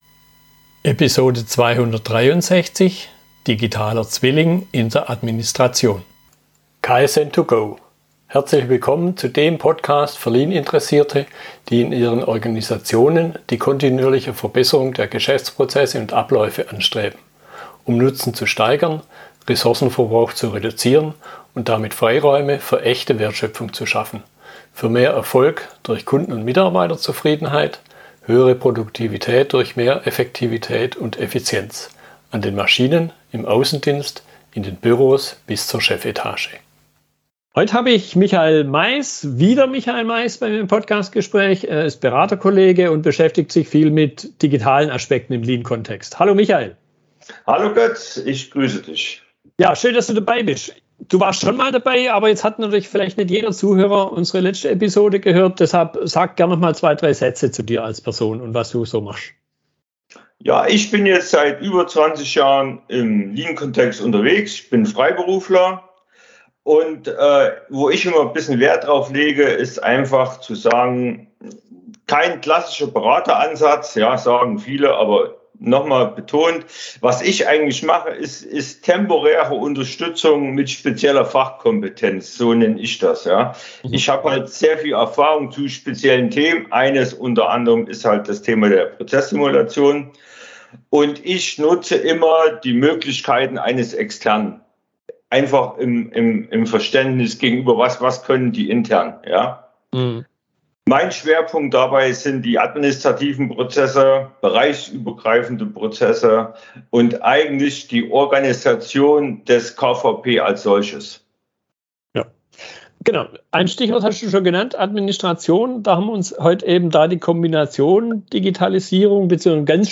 Unterhaltung